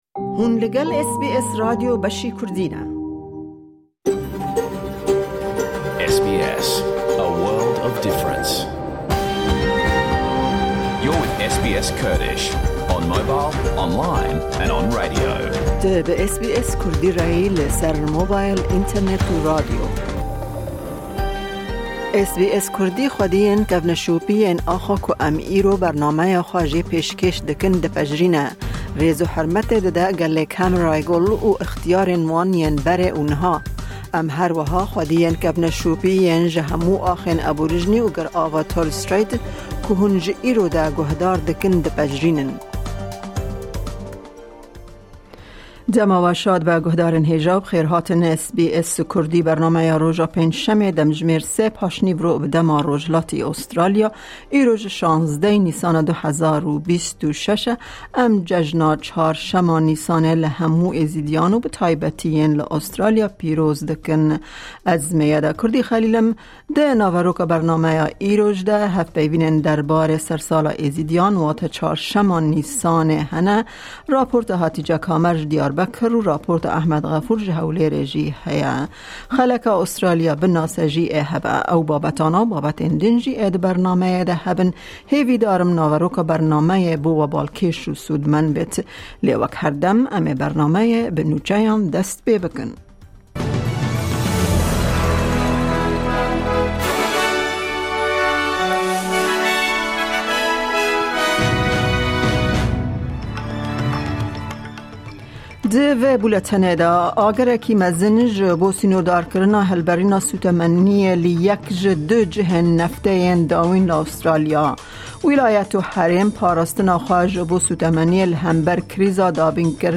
Nûçe, raportên ji Hewlêr û Amedê, hevpeyvînên bi kesên Êzîdî re derbarê Cejina Çarşema Nîsanê û gelek babetên din yên cur bi cur di naveroka bernameyê de tên dîtin.